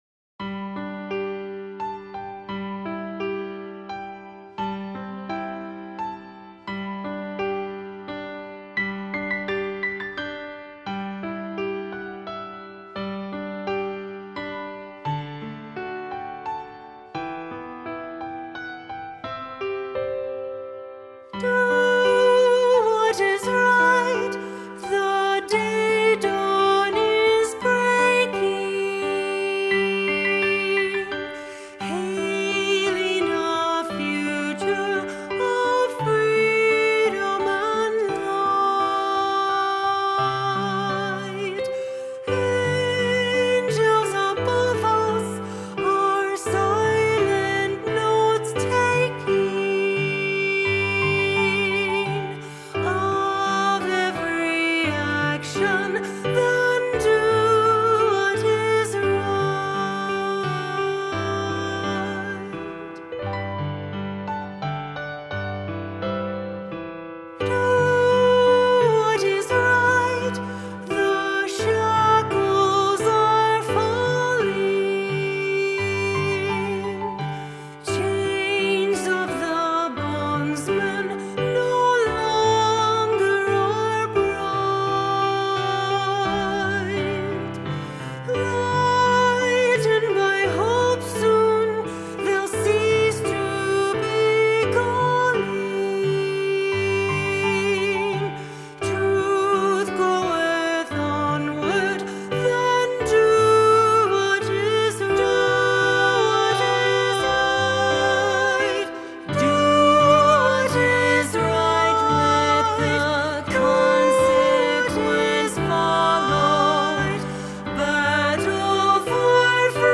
Vocal Duet for Medium and Medium-high Voices with Piano